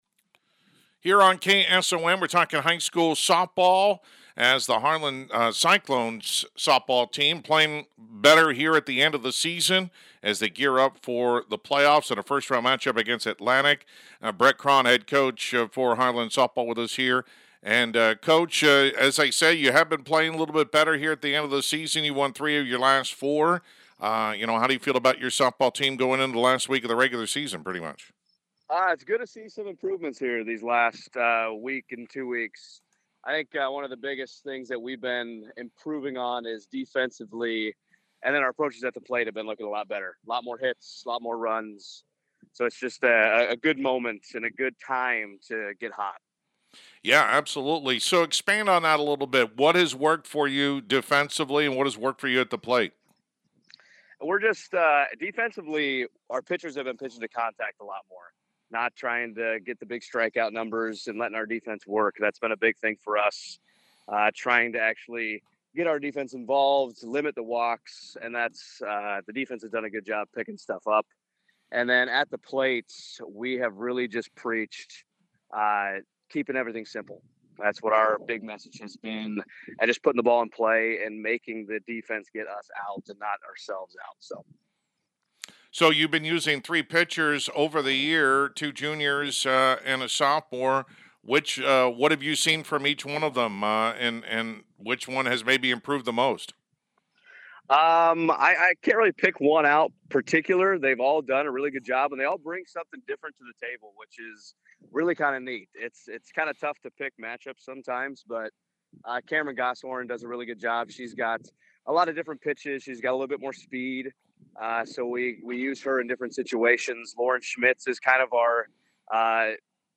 harlan-softball-6-30.mp3